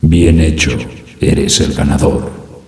winner.ogg